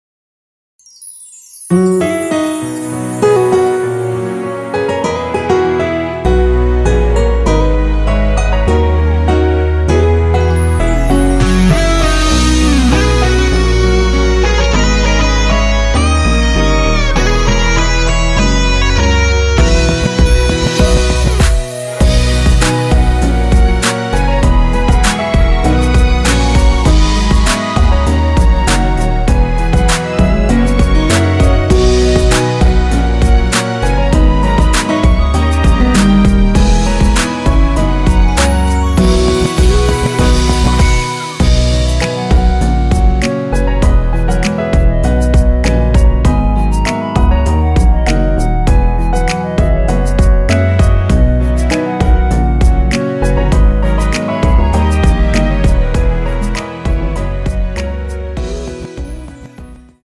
원키 멜로디 포함된 MR 입니다.(미리듣기 참조)
앞부분30초, 뒷부분30초씩 편집해서 올려 드리고 있습니다.
중간에 음이 끈어지고 다시 나오는 이유는